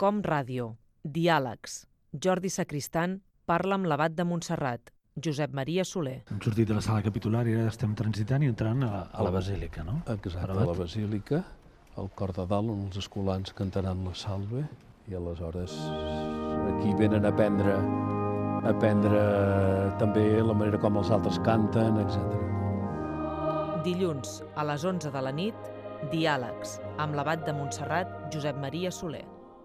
Promoció de l'edició del programa en el qual s'entrevista a l'abat de Montserrat, Josep Maria Solé